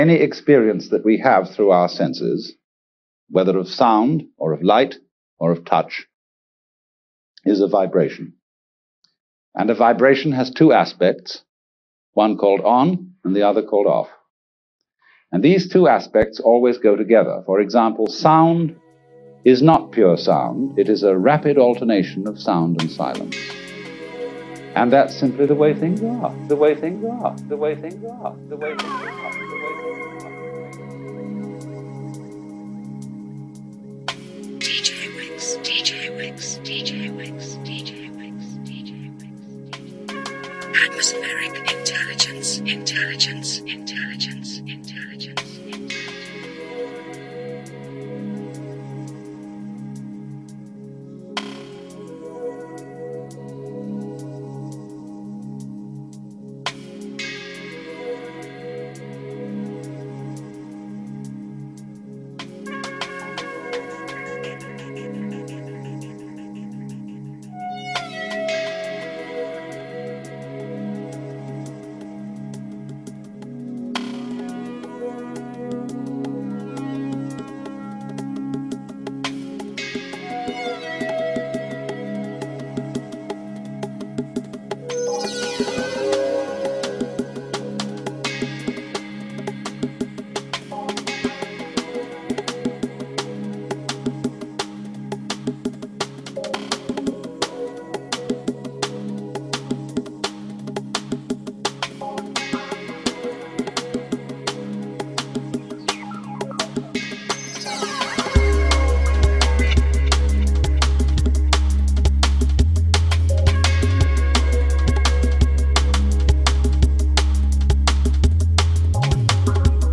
atmospheric, drum & bass